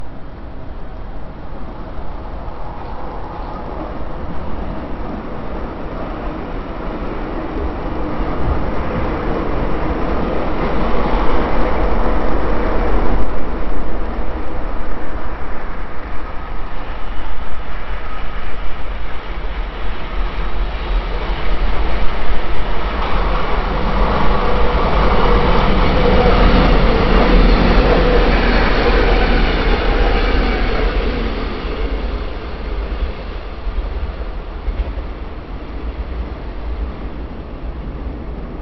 Enregistrement St Maur
Fret ITE Toulouse
Enregistrement-audio-passage-de-trains.mp3